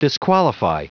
Prononciation du mot disqualify en anglais (fichier audio)
Prononciation du mot : disqualify